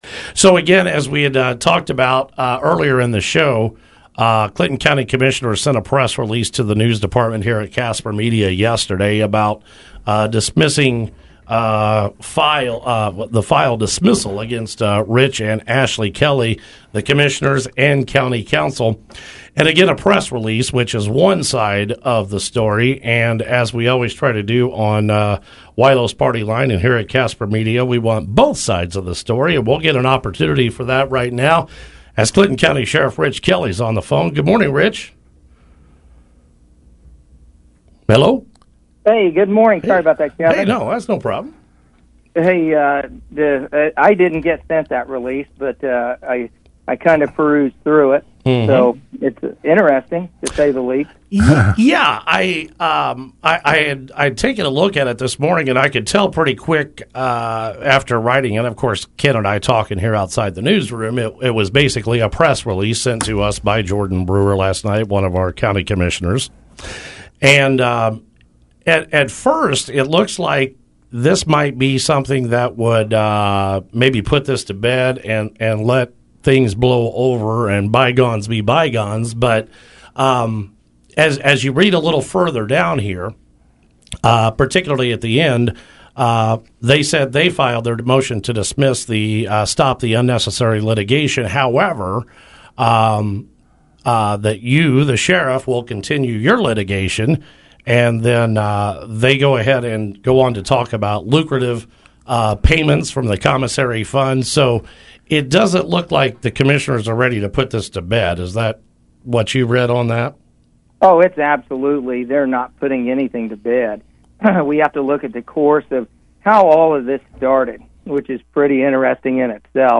Clinton County Sheriff Rich Kelly called the Partyline Program Wednesday morning to discuss the litigation with Clinton County Commissioners and specifically, the press release issued by them Tuesday evening.